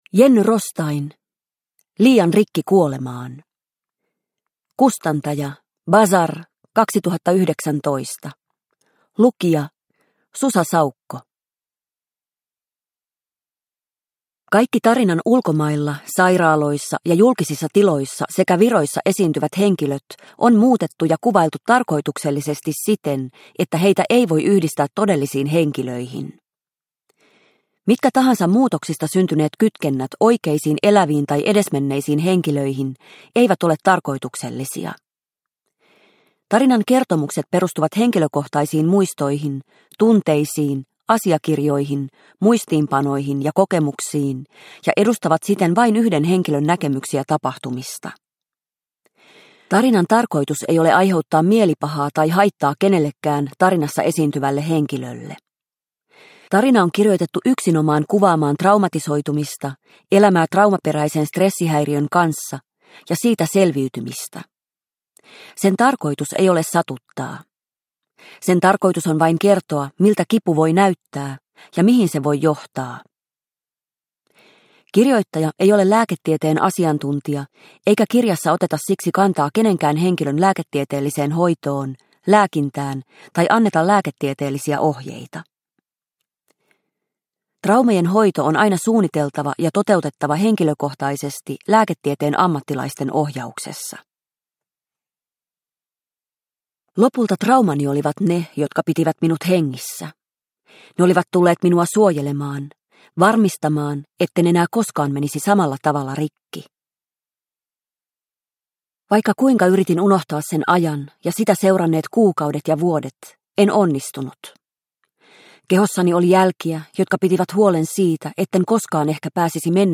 Liian rikki kuolemaan – Ljudbok – Laddas ner